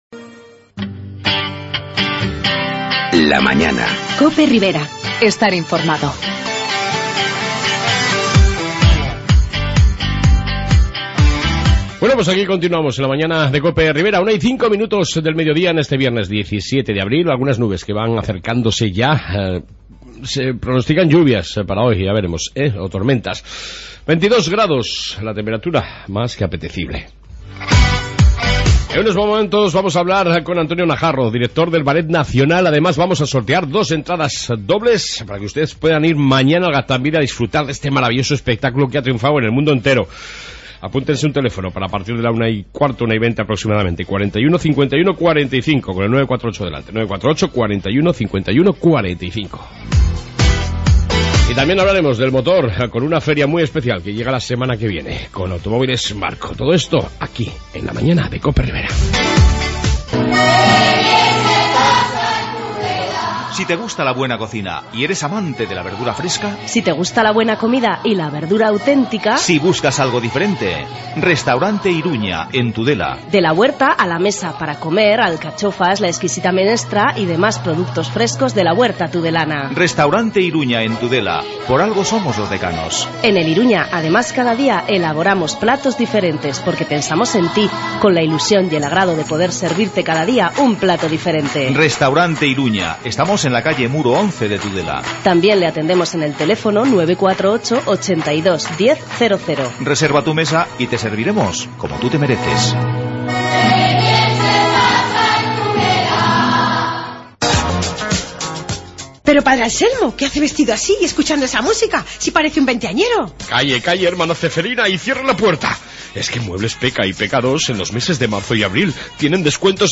AUDIO: Entrevista con el Director del ballet nacional Antonio Najarro y programa sobre motor con Automoviles marco